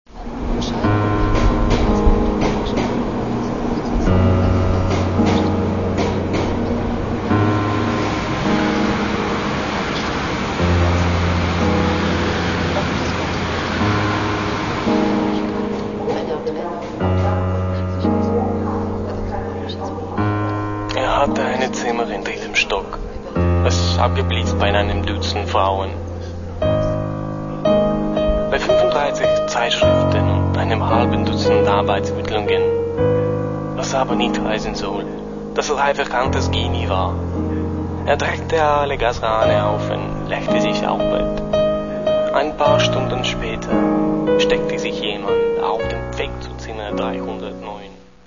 Каталог -> Поп (Легка) -> Fashion